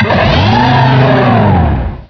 sovereignx/sound/direct_sound_samples/cries/samurott.aif at master